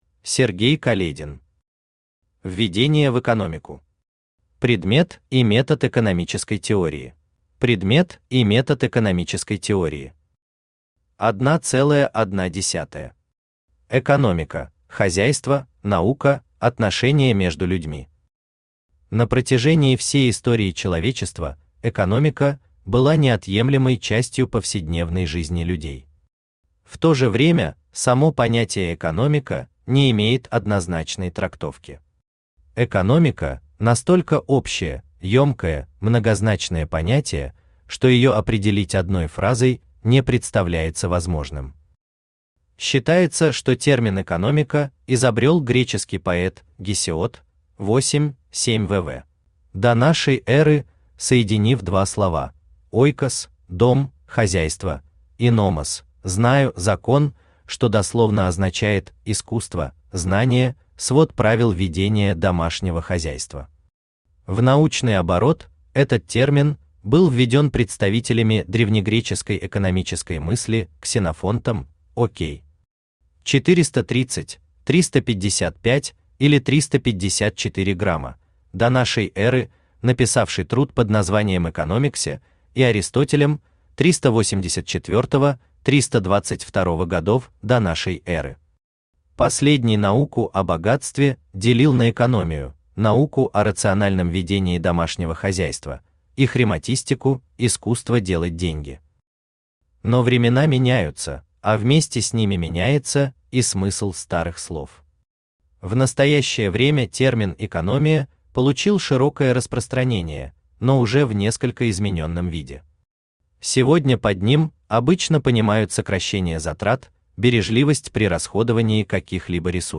Аудиокнига Введение в экономику. Предмет и метод экономической теории | Библиотека аудиокниг
Предмет и метод экономической теории Автор Сергей Каледин Читает аудиокнигу Авточтец ЛитРес.